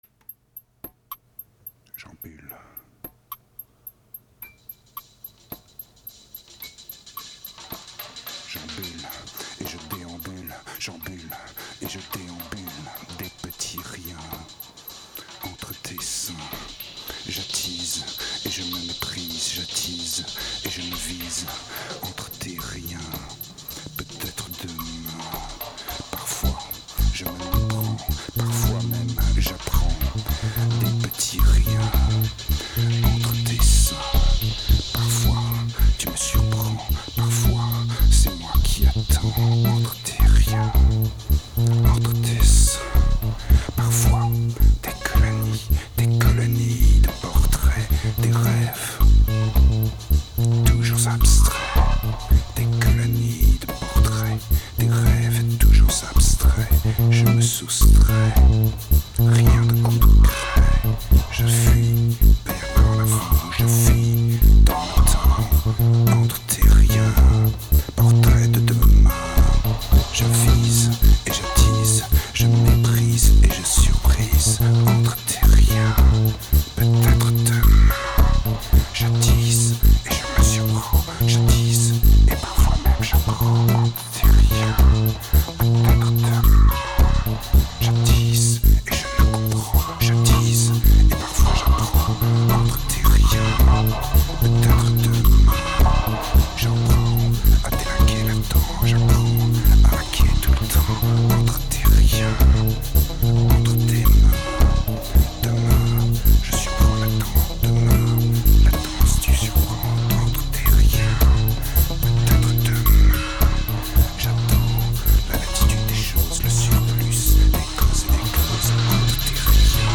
2215📈 - -78%🤔 - 109BPM🔊 - 2008-10-17📅 - -468🌟